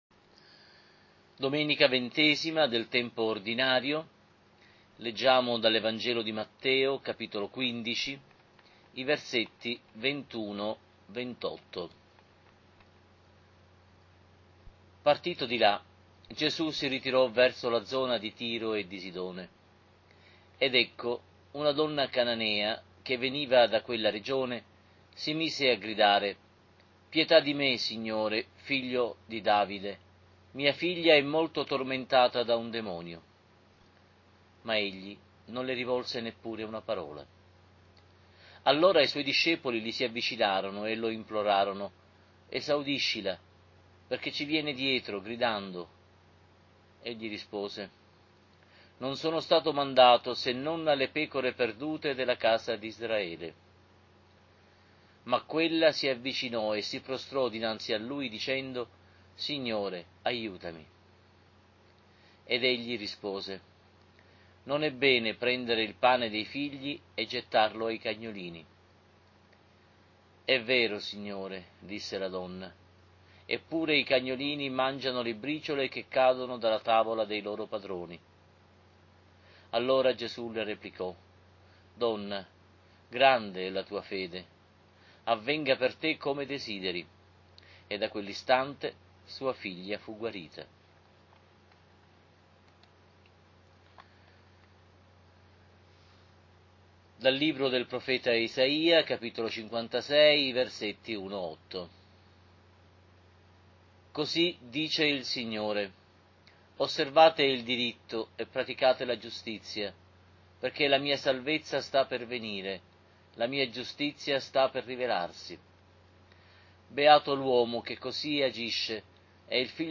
Lectio divina Domenica “DELLA DONNA CANANEA”, XX del Tempo Ordinario A - Abbazia di Pulsano. Sito ufficiale